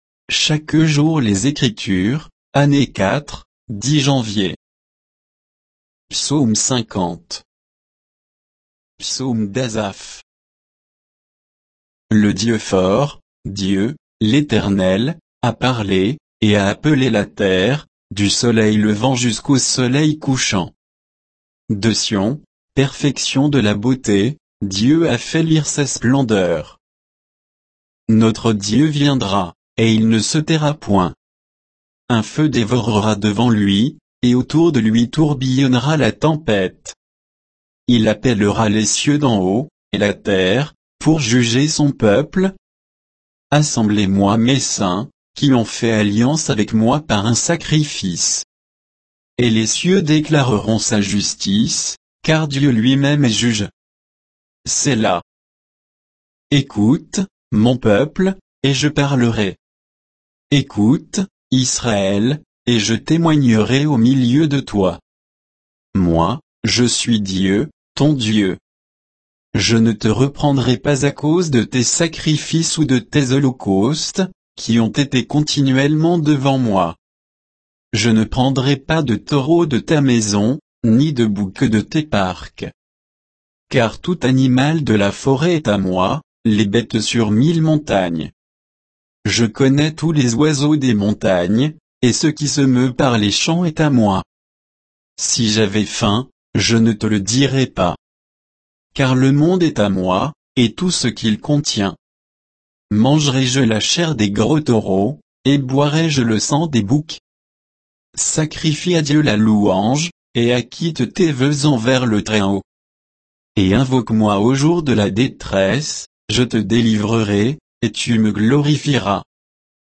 Méditation quoditienne de Chaque jour les Écritures sur Psaume 50